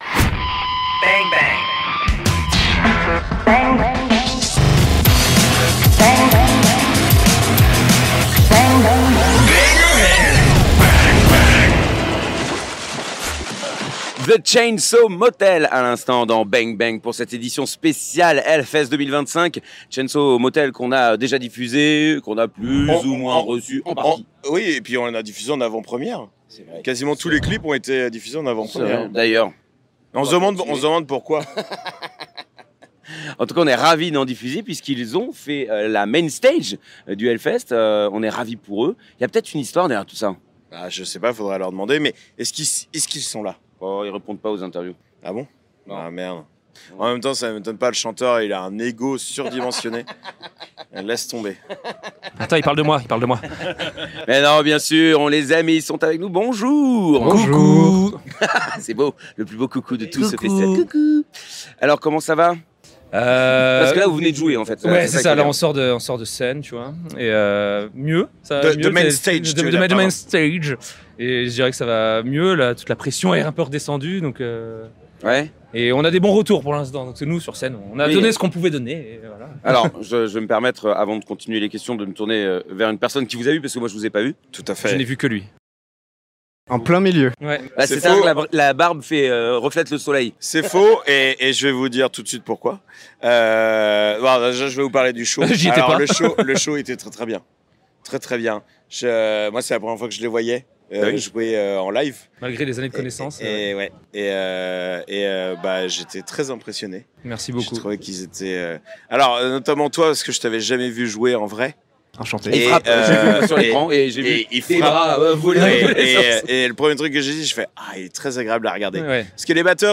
Et nous avons fait 23 interviews !